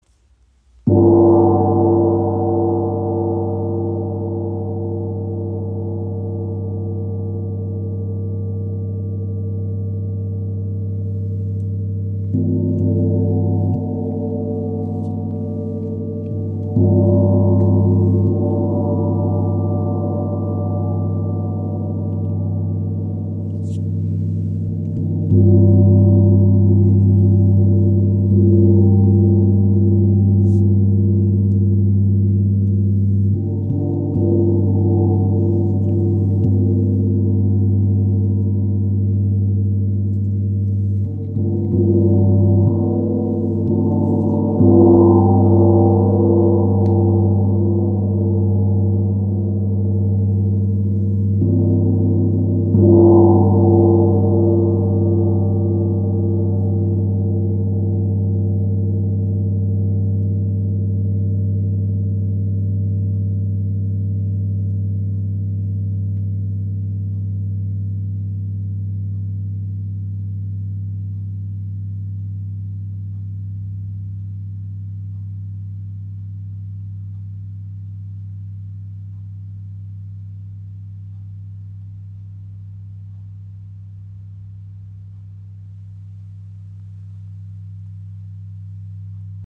Gong | Nickel Aloy Gong | Ø 70 cm im Raven-Spirit WebShop • Raven Spirit
Tam Tams/Chau Gongs zeichnen sich durch einen rundum nach hinten geschmiedeten Rand aus, welcher den Klangcharakter mitbestimmt. Der Sound ist voluminös und erinnert im Aufbau an übereinander liegende Klangebenen. So kann ein Soundgebilde aus Bass und Obertönen entstehen. Solche Gongs mit Rand erzeugen einen sehr meditativen Gesamtklang.